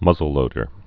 (mŭzəl-lōdər)